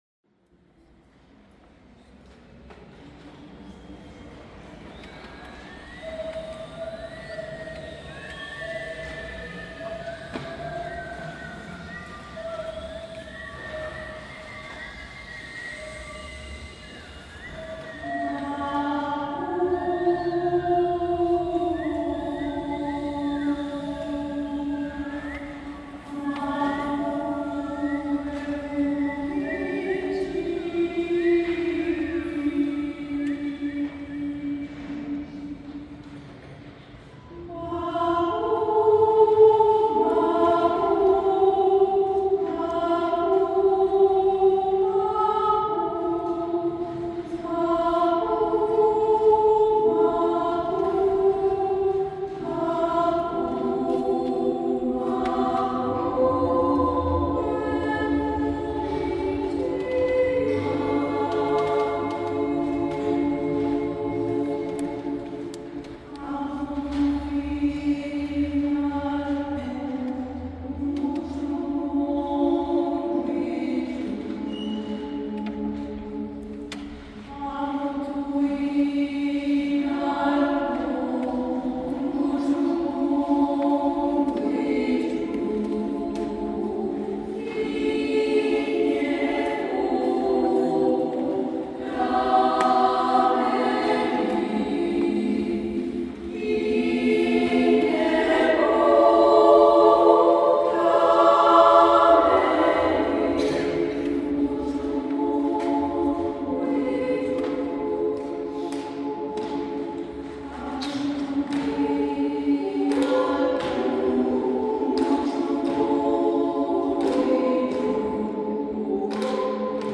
El pasado 3 de diciembre, durante el Concierto de fin de año ofrecido por el Coro Universitario del Comahue y el Coro de Cámara del IUPA en la Catedral de General Roca, tuvieron su estreno las obras corales «Mapu!
Mapu! Wellüm Inchin (¡Tierra! nosotros te cuidaremos), de la compositora Laura Alberti, es una obra a tres voces que incorpora percusión corporal.
El estreno estuvo a cargo de las sopranos y contraltos del Coro Universitario del Comahue
El 1° Concurso de Composición Coral «Aimé Painé» es una iniciativa interinstitucional impulsada por Delegación Alto Valle de ADICORA y la Mesa Coral Universitaria de Rio Negro y Neuquén, bajo el auspicio de la Secretaría de Extensión de la Universidad Nacional del Comahue, la Facultad de Lenguas de la UNCo, el Instituto Universitario Patagónico de las Artes y la ADICORA Nacional, y tiene por objeto la promoción de las lenguas y las culturas originarias a través de la creación de piezas inéditas a capela.